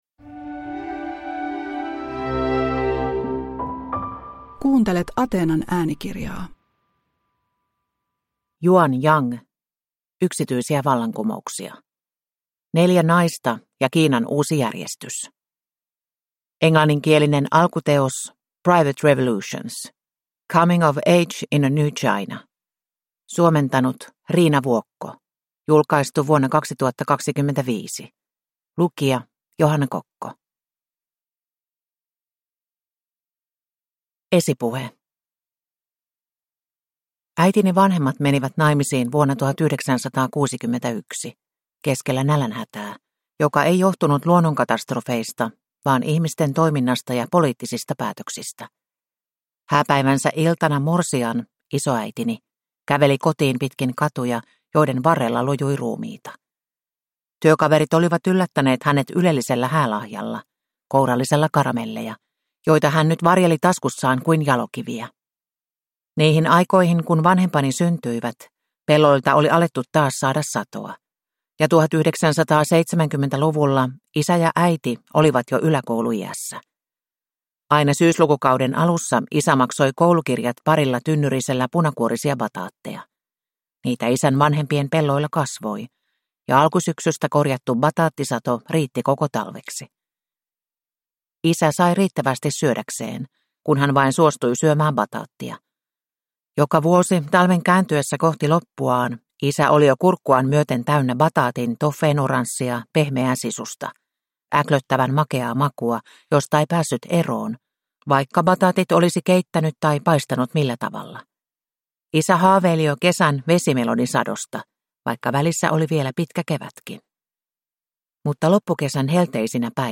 Yksityisiä vallankumouksia (ljudbok) av Yuan Yang